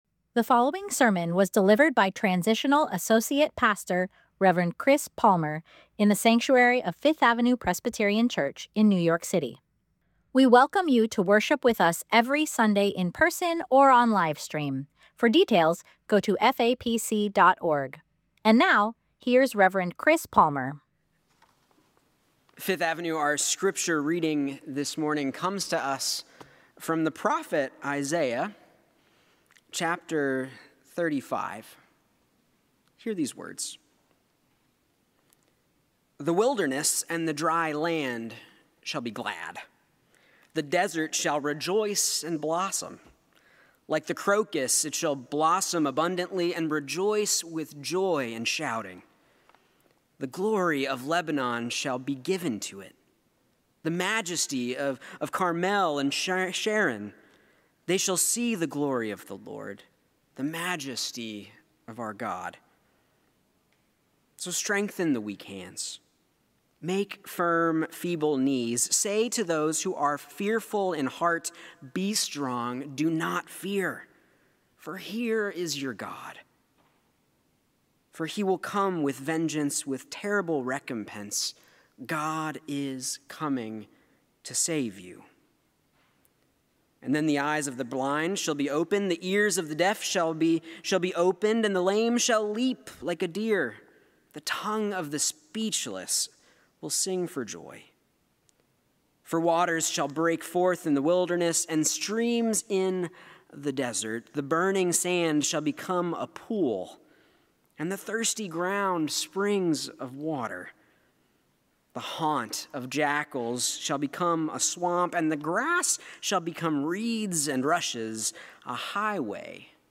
Sermon: “When Tears Pray” Scripture: Isaiah 35